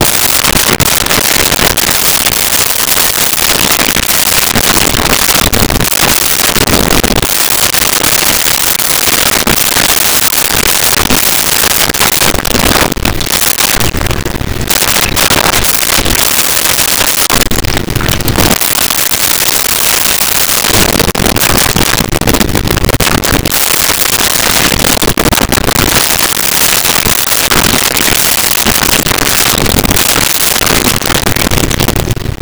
Bath Water Movement
Bath Water Movement.wav